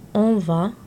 Click each word to hear the pronunciation.